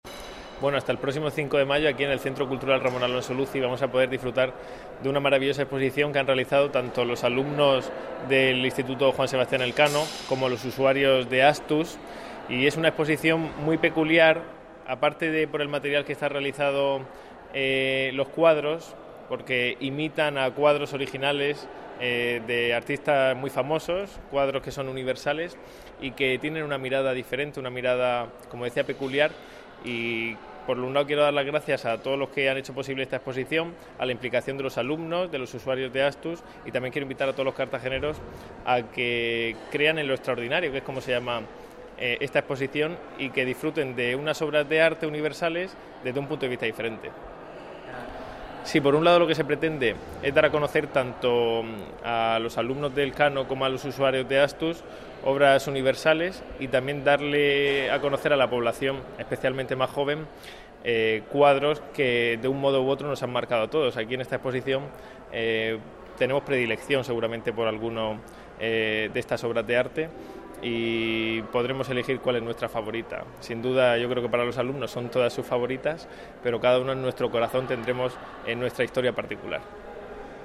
Enlace a Declaraciones del concejal de Cultura y Educación, Ignacio Jáudenes